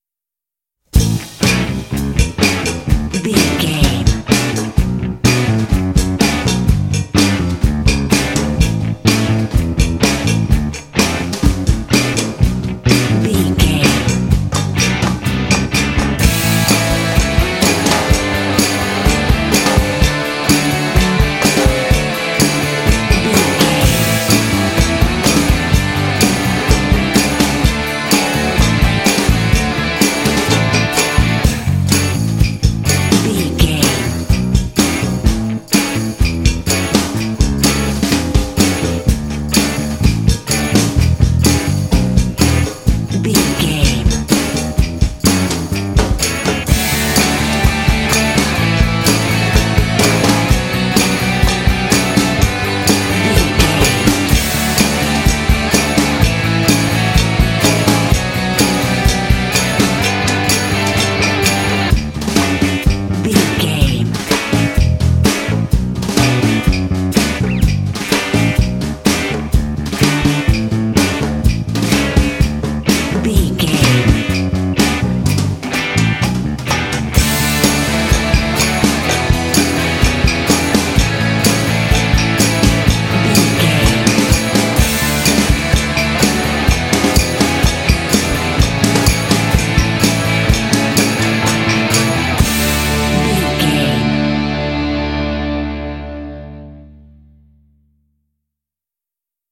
Ionian/Major
D
driving
cheerful/happy
lively
bass guitar
drums
electric guitar
percussion
classic rock